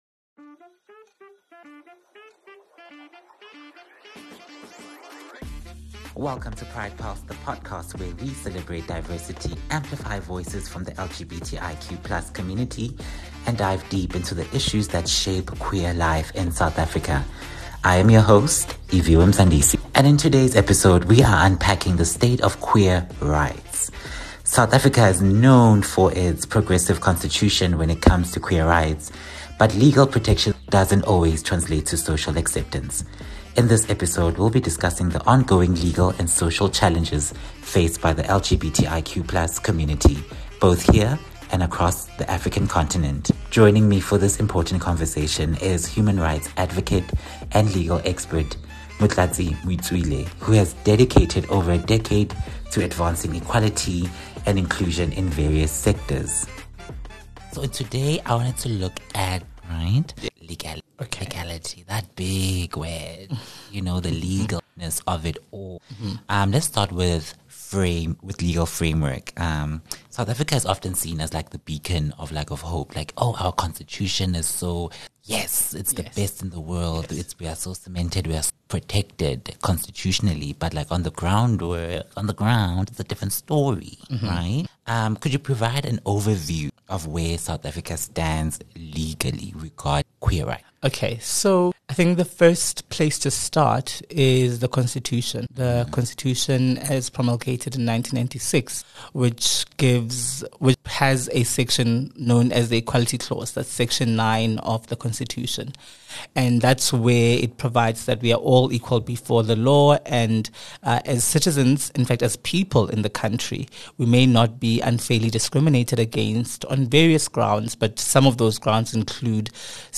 Together, we discuss how far we've come, the remaining barriers, and what needs to be done to ensure genuine equality and inclusion. Tune in for a compelling conversation about the fight for justice and equality across South Africa and the African continent.